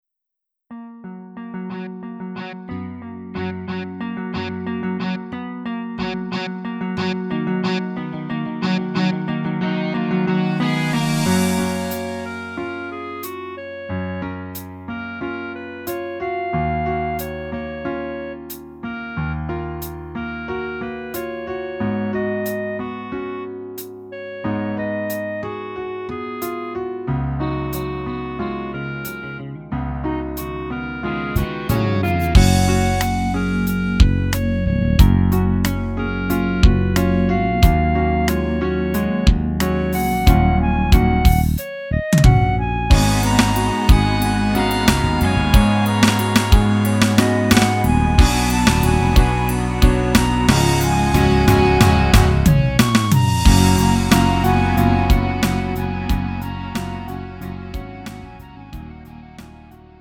음정 -1키 3:45
장르 가요 구분 Lite MR